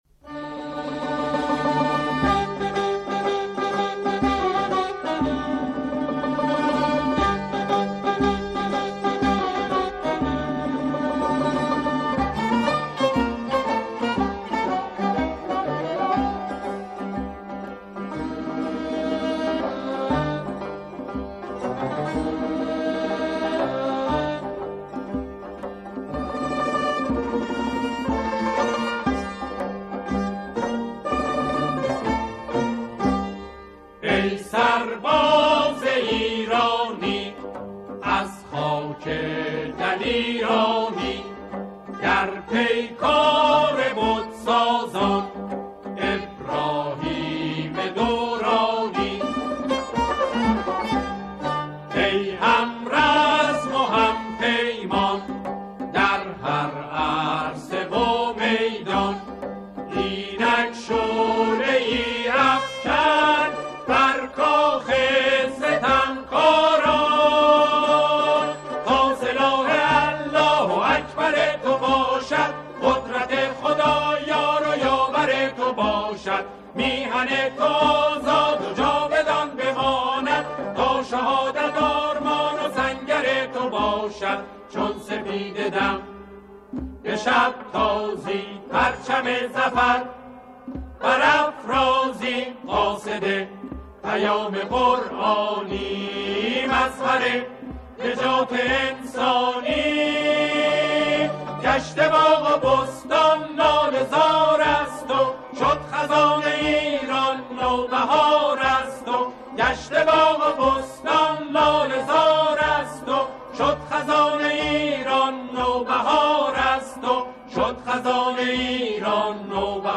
آنها در این قطعه، شعری را درباره دفاع مقدس همخوانی می‌کنند.